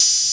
SB - Goosebumps (OpenHat).wav